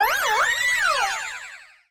diancie_cry.ogg